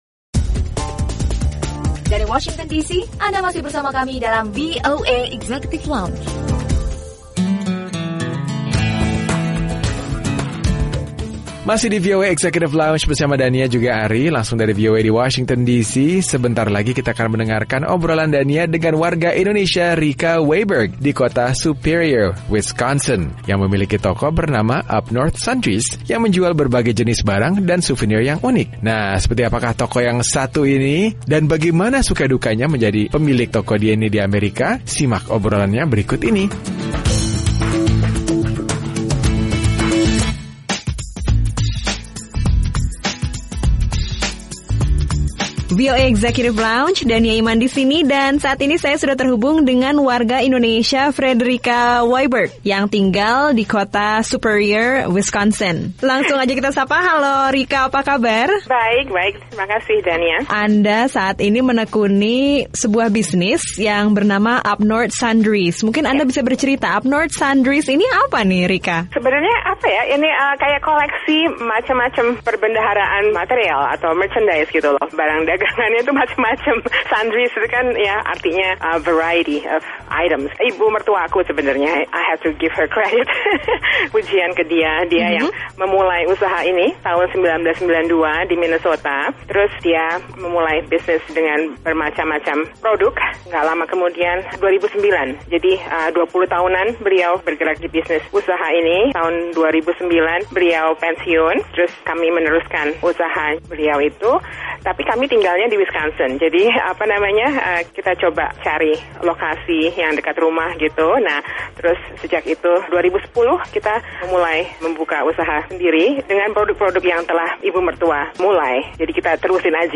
Obrolan bersama diaspora Indonesia yang memiliki dan menjalankan usaha toko serba ada dengan beragam souvenir unik di negara bagian Wisconsin.